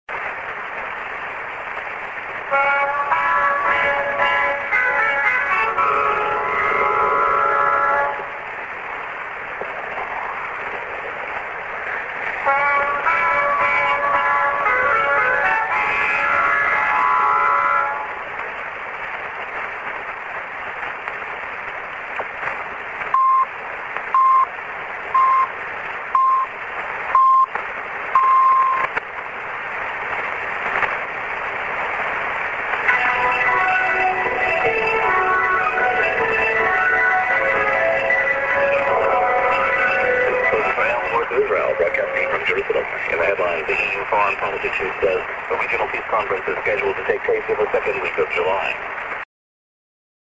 St. IS->TS->ID(man)